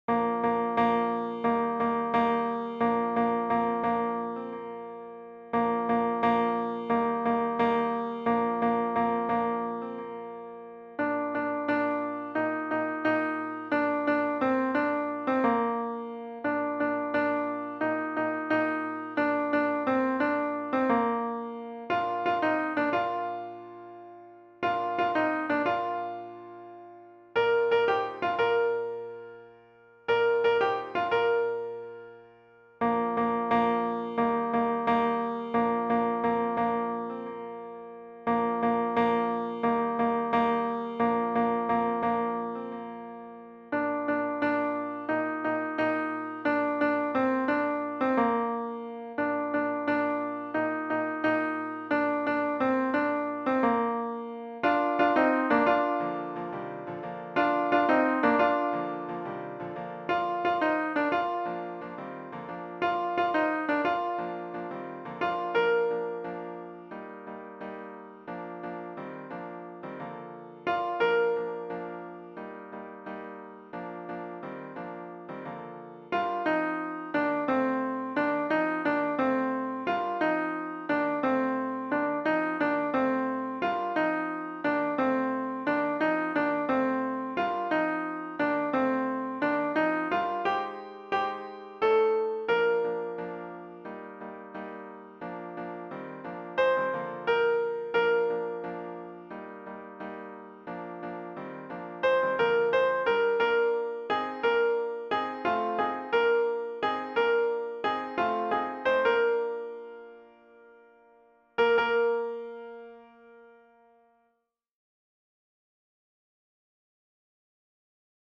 FJqqYFZjYLp_African-Noël-Choeur-Alto.mp3